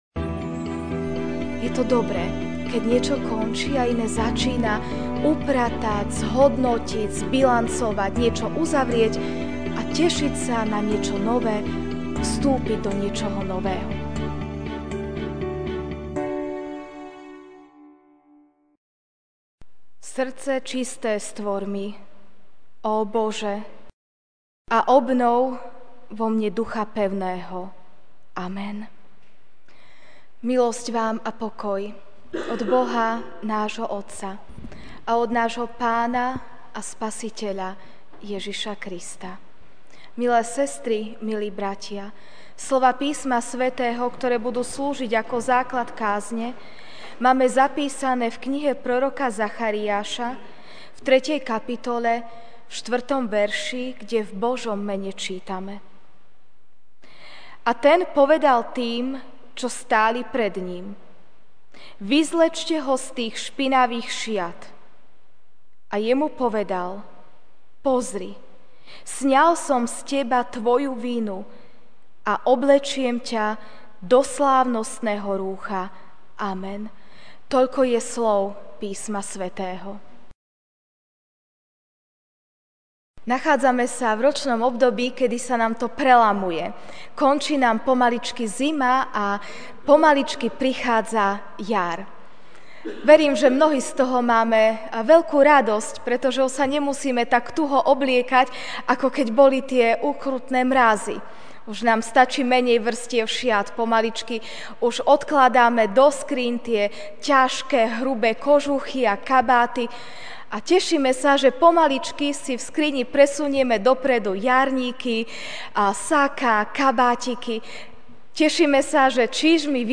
Večerná kázeň: Niečo končí, iné začína (Zach. 3, 4) Ten povedal tým, čo stáli pred ním: Vyzlečte ho z tých špinavých šiat!